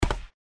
stepstone_1.wav